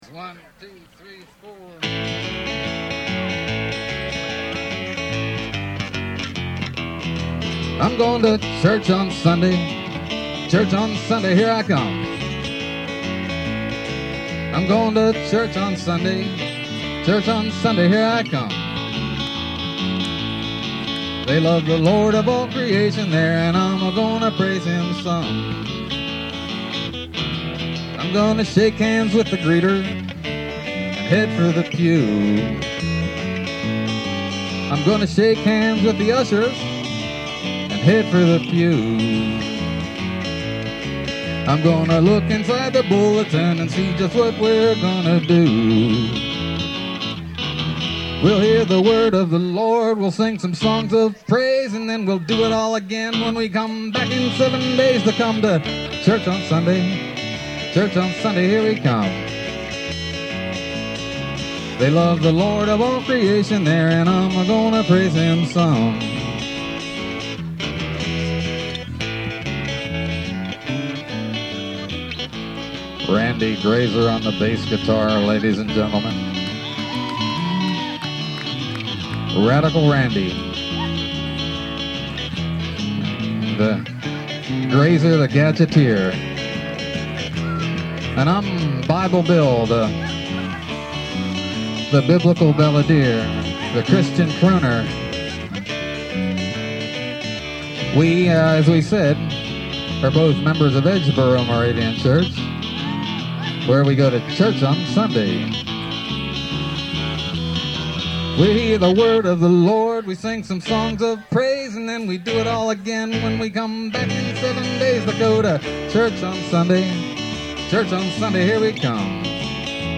live at the BAM Picnic